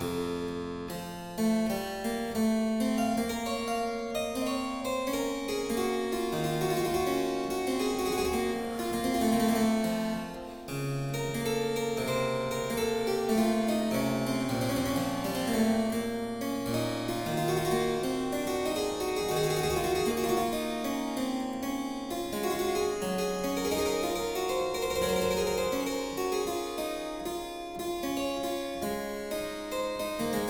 clavecin